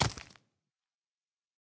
sounds / mob / zombie / step5.ogg
step5.ogg